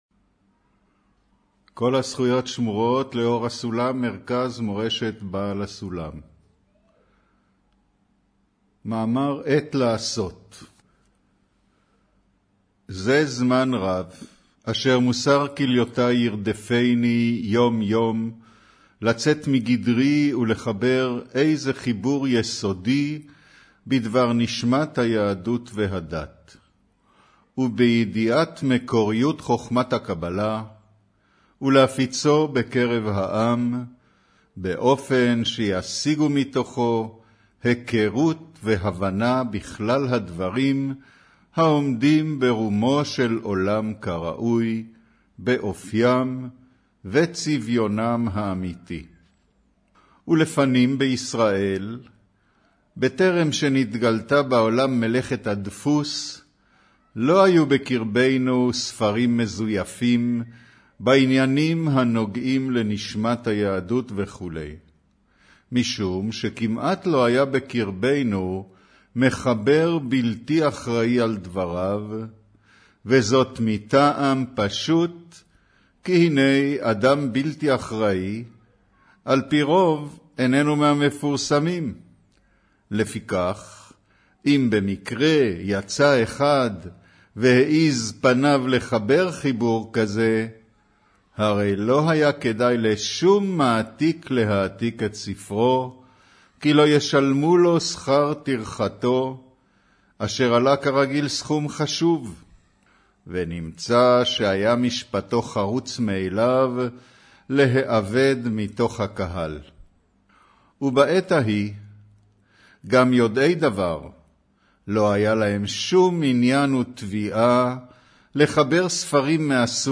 אודיו - שיעור מבעל הסולם עת לעשות